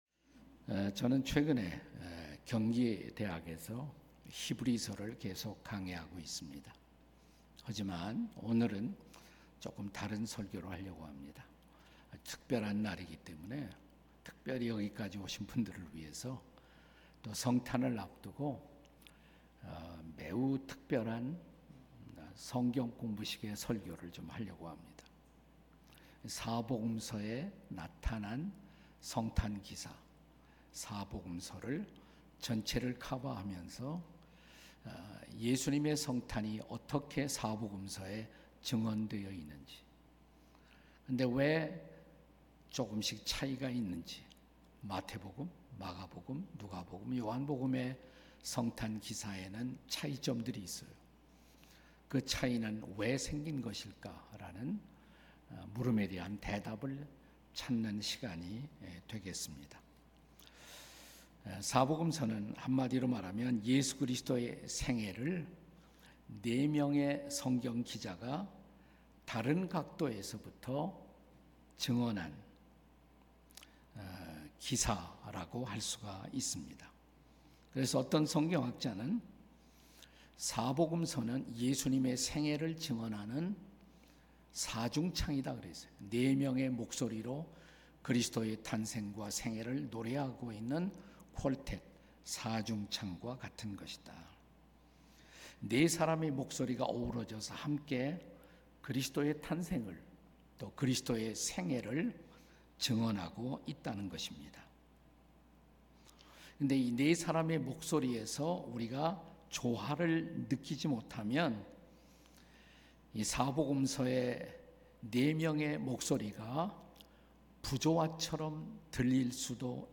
설교 : 주일예배 4복음서의 성탄 기사는 왜 차이가 있을까요?